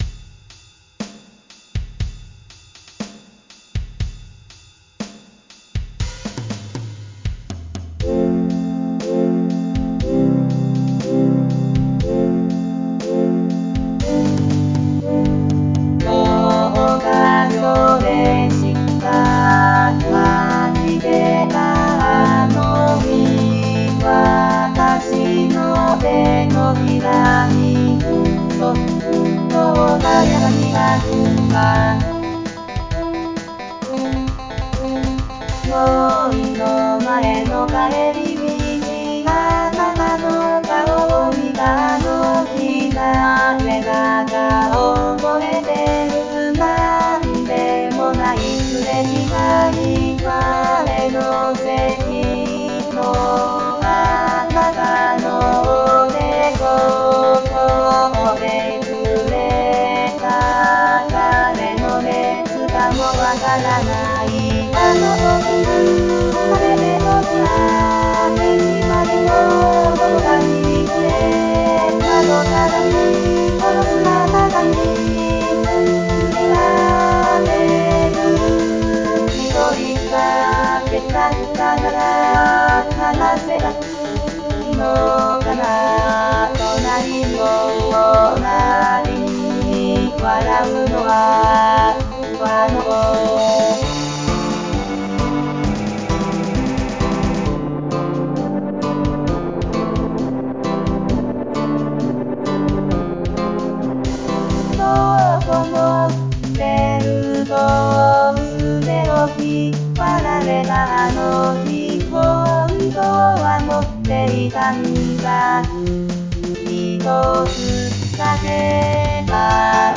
二重唱